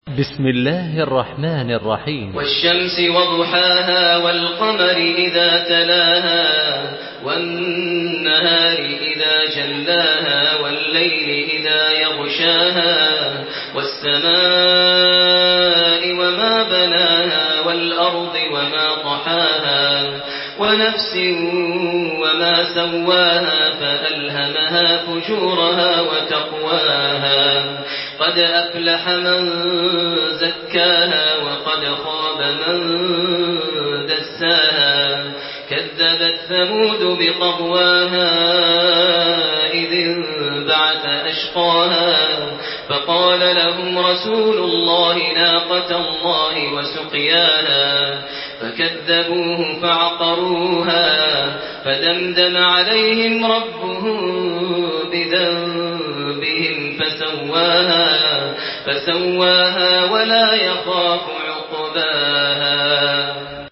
تحميل سورة الشمس بصوت تراويح الحرم المكي 1428
مرتل حفص عن عاصم